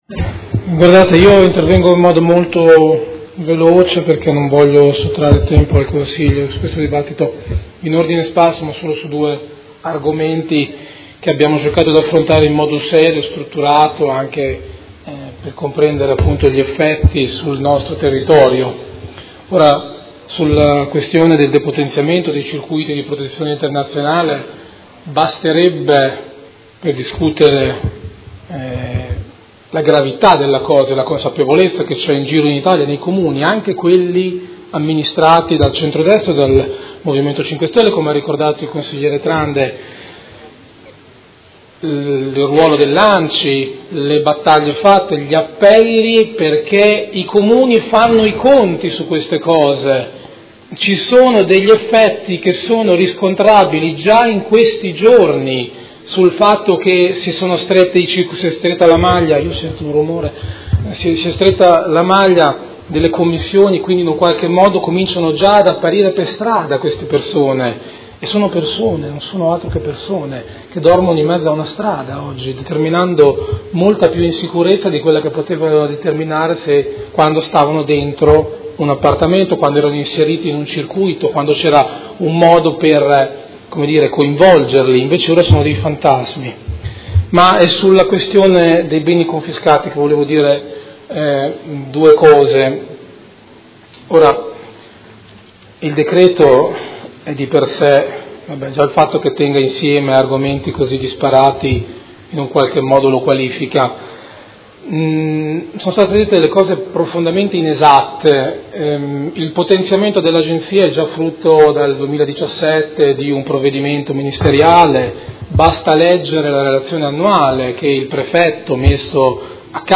Seduta del 29/11/2018 Dibattito congiunto su Ordine del Giorno Prot. Gen. n.158233 e Ordine del Giorno Prot. Gen. n. 168296 e Ordine del Giorno Prot. Gen. n. 175105